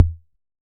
Index of /musicradar/retro-drum-machine-samples/Drums Hits/Raw
RDM_Raw_SR88-Kick.wav